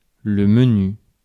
Ääntäminen
US
IPA : /maɪnə/